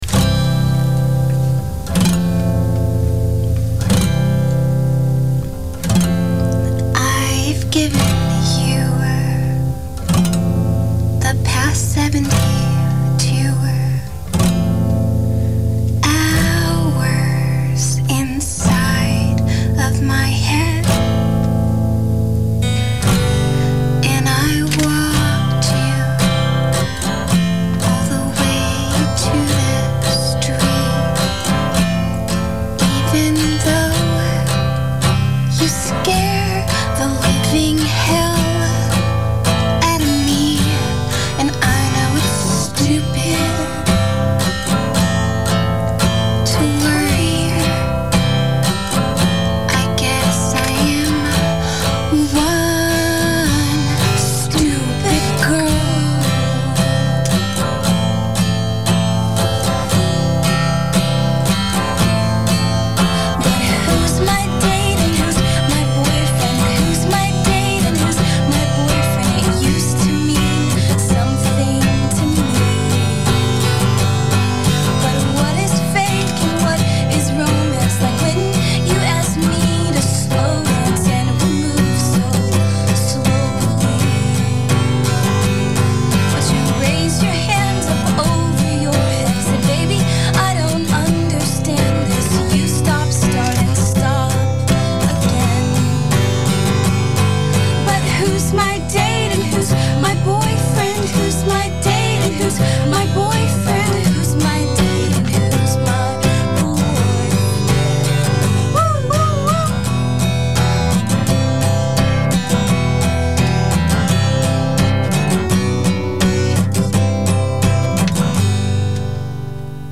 sessions